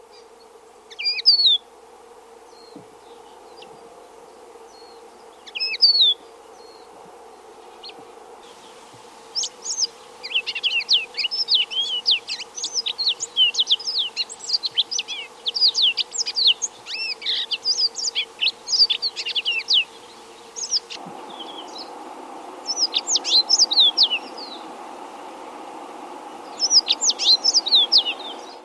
Yellow-fronted Canary
Yellow-fronted_Canary.mp3